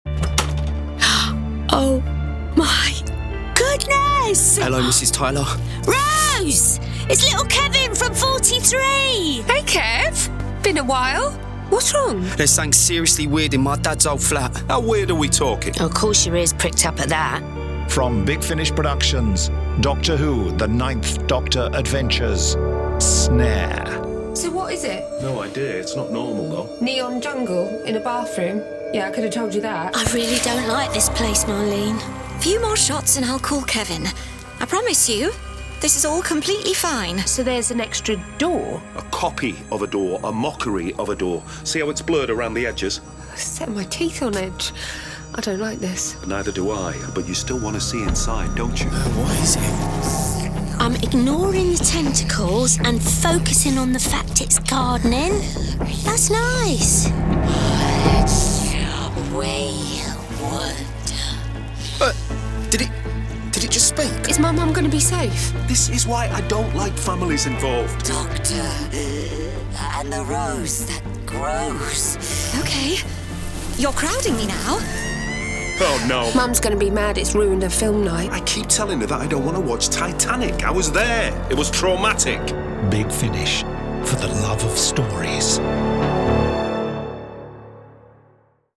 Starring Christopher Eccleston Billie Piper